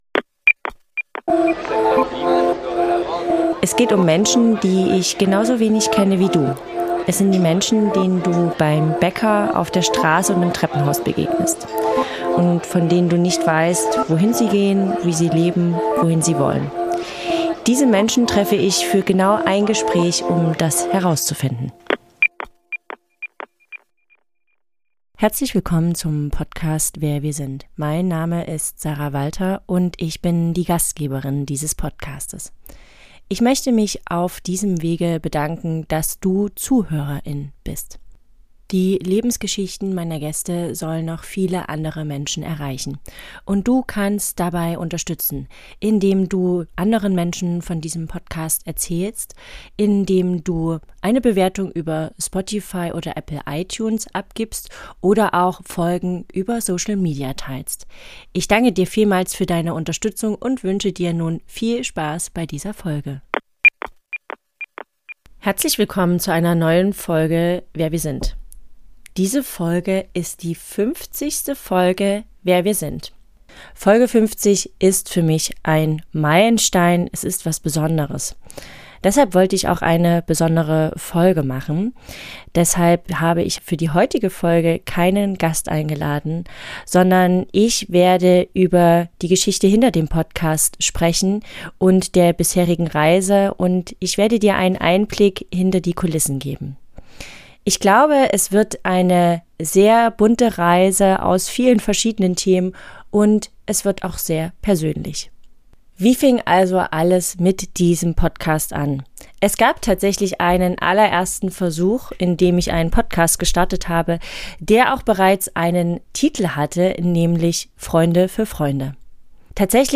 Wer wir sind. Folge 50 - Jubiläumsfolge ~ Wer wir sind. - ein Gespräch. ohne Skript. ohne Labels.